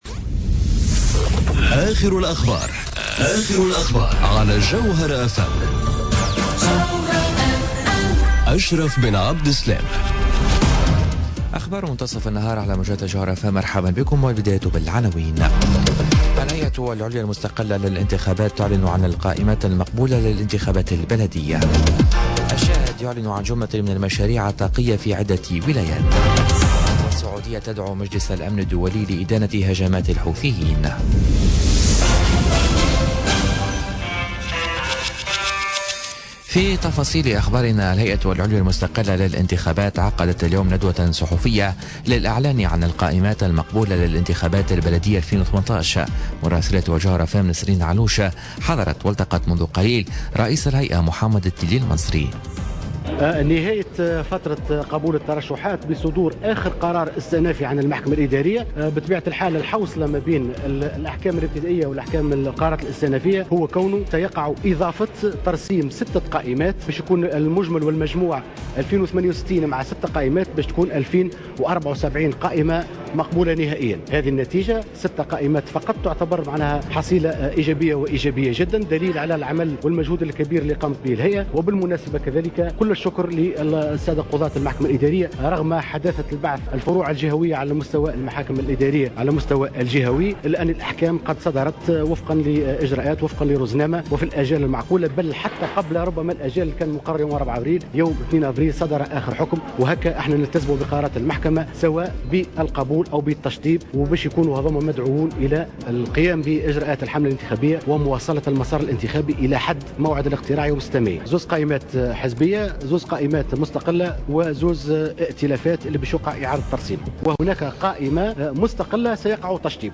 نشرة أخبار منتصف النهار ليوم الخميس 5 أفريل 2018